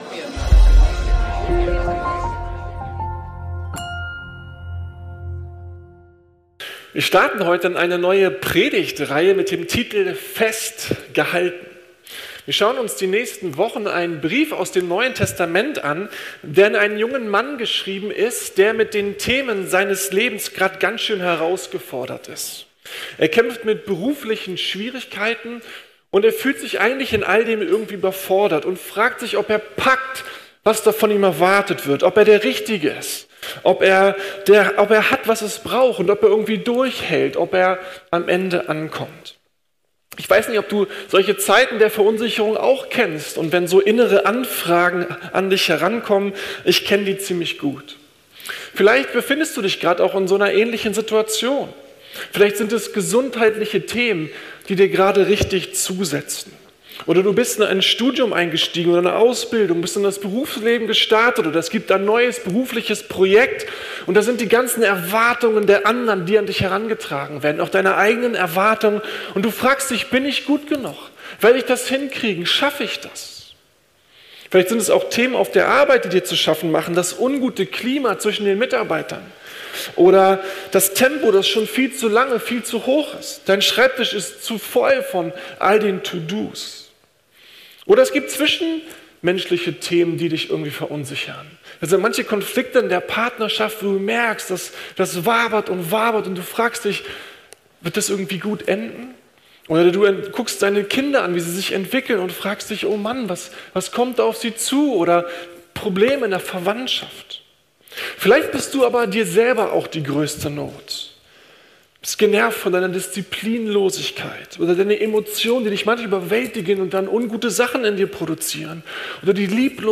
Überwinde deine Furcht! ~ Predigten der LUKAS GEMEINDE Podcast